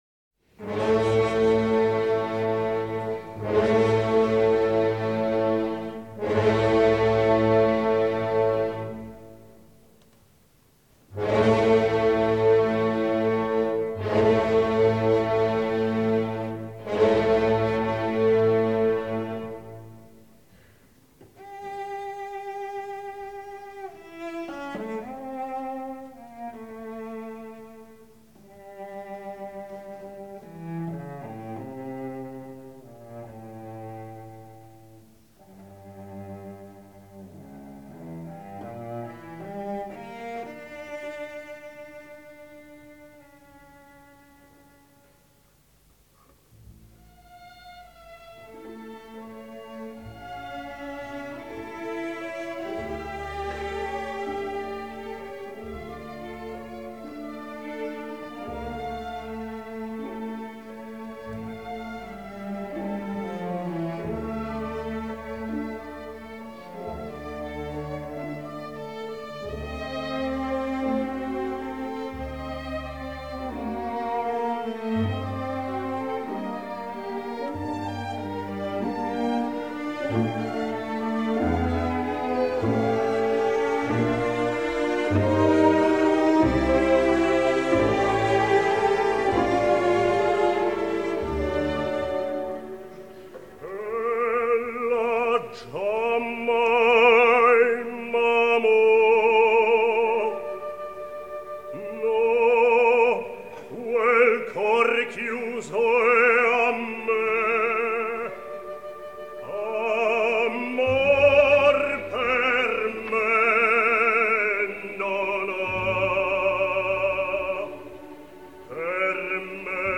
José Van Dam, Bass-baritone. Giuseppe Verdi: Ella Giammai m’ macò (King Philippe’s aria, Act IV). From “Don Carlos”.
Orchestre de la Suisse Romande.